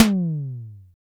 BENDYTOM HI.wav